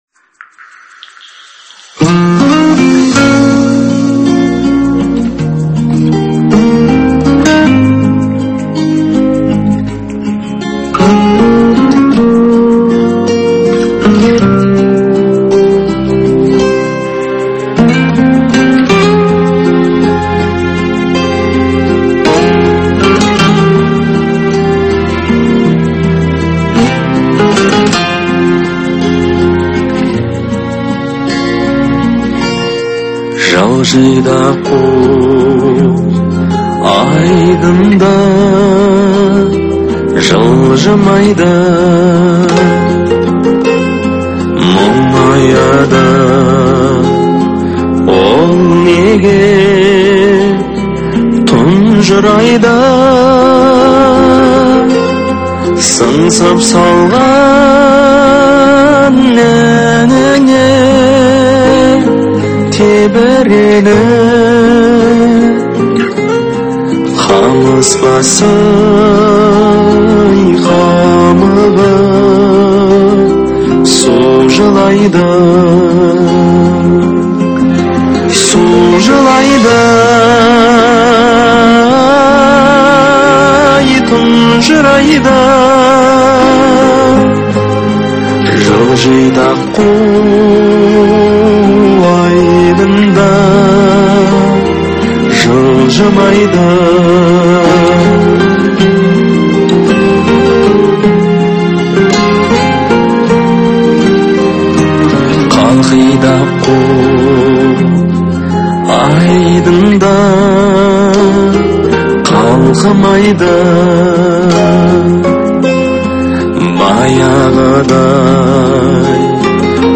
это трогательная песня в жанре казахской эстрадной музыки